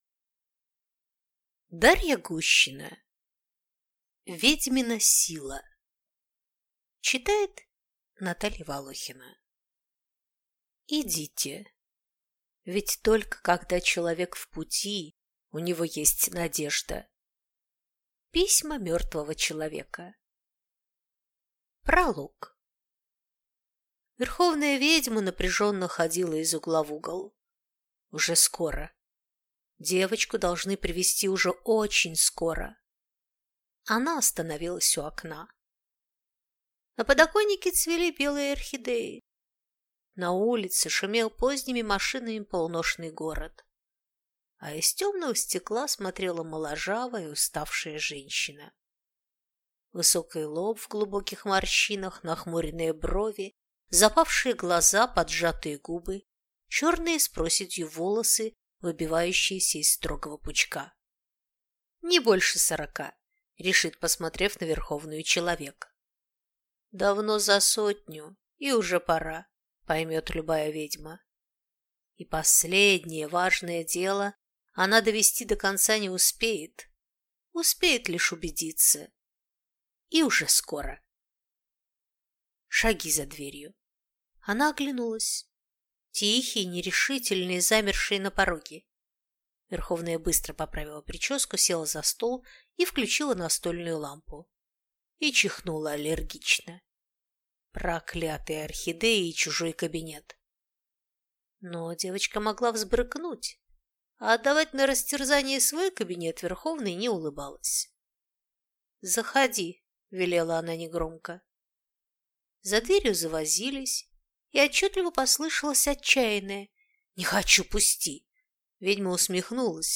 Аудиокнига Ведьмина сила | Библиотека аудиокниг